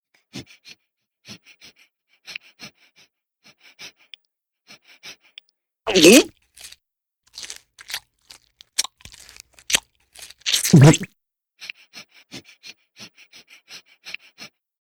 beg_eat_swallow.ogg